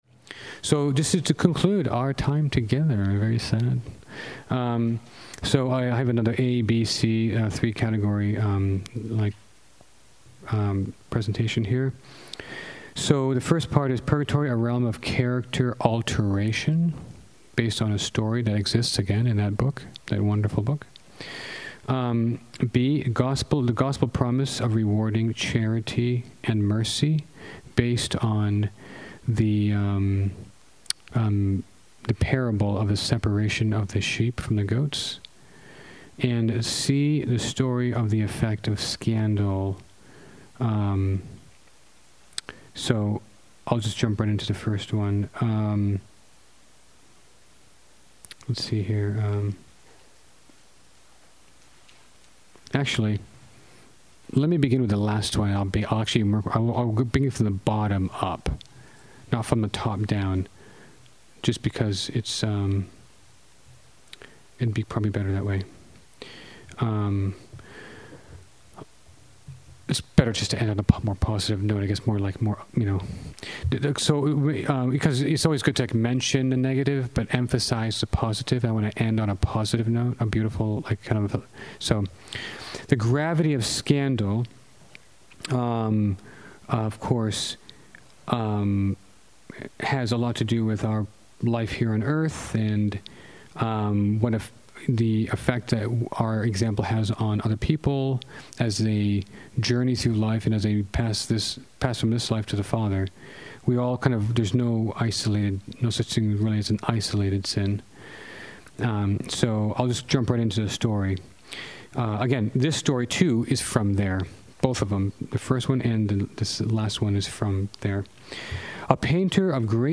preaches a retreat on purgatory called November, Month of Holy Souls. In this last talk, he covers the gravity of scandal, character alteration, and reward for charity.